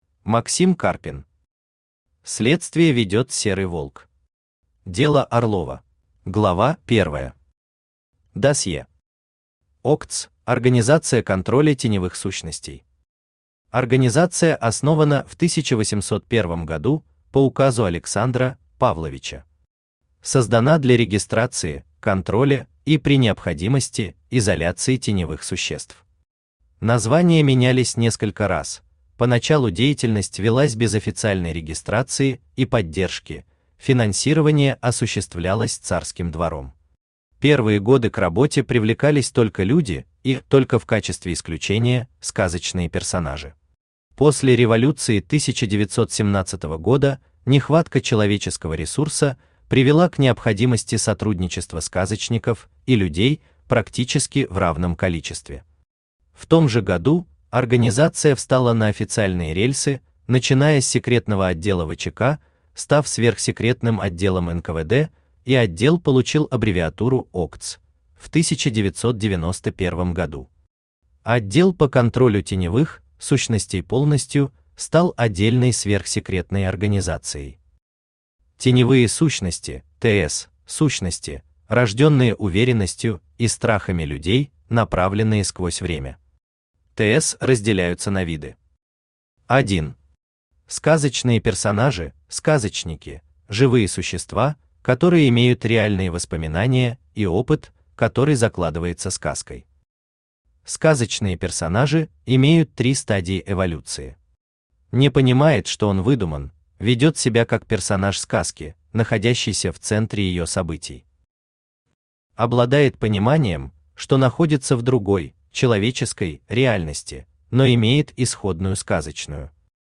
Аудиокнига Следствие ведет Серый Волк. Дело Орлова | Библиотека аудиокниг
Читает аудиокнигу Авточтец ЛитРес